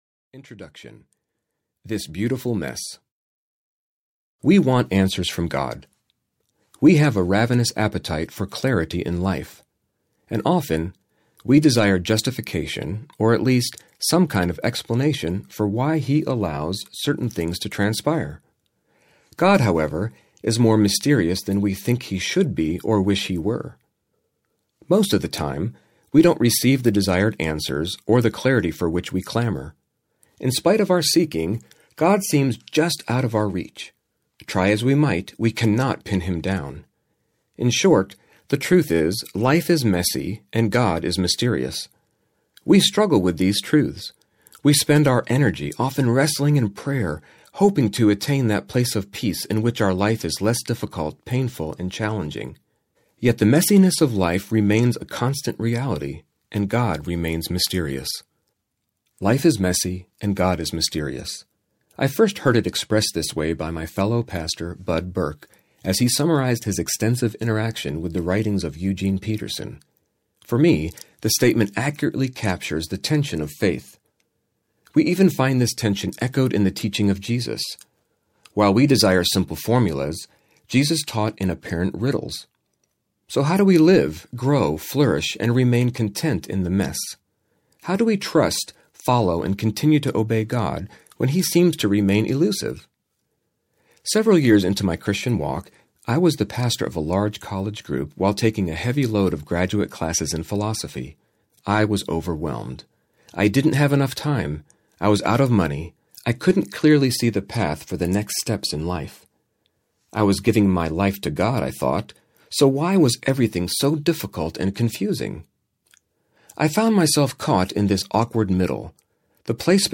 The Grand Paradox Audiobook
6.6 Hrs. – Unabridged